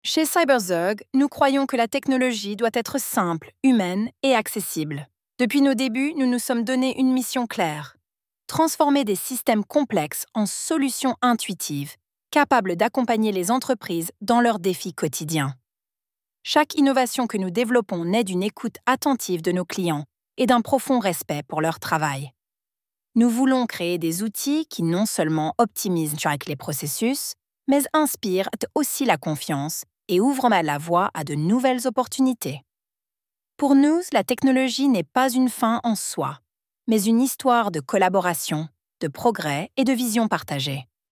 Использованный стиль – «Рассказ».
Голос звучит очень отчетливо, с ударениями, которые местами кажутся даже несколько форсированными.
Однако паузы расставлены грамотно, интонация логичная, произношение – чистое и уверенное.